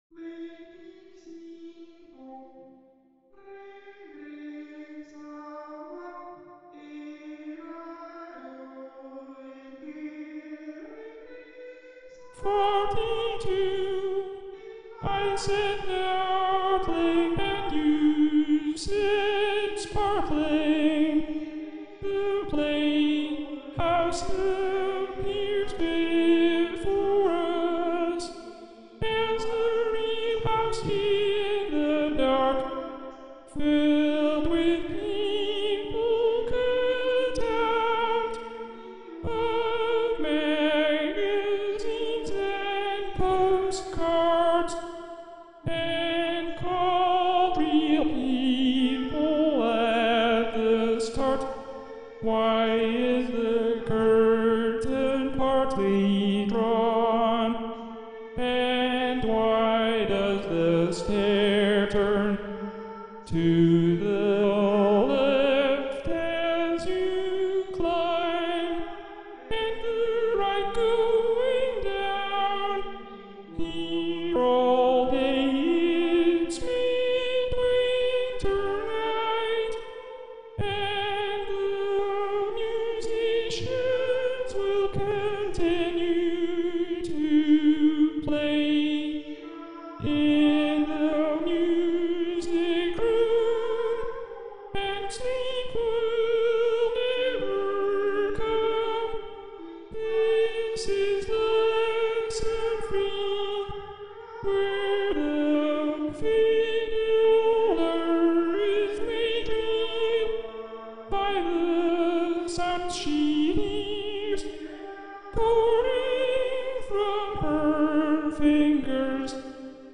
sound installation
Each message sent from the web site is sang in the restrooms by an electronic agent.
The algorithm plays back the message at the selected bathroom; the electronic sound is digitally reverberated to transform the acoustics of the site in order to create the illusion of voices singing in a small chapel.
Sound recording done at women bathroom
female.mp3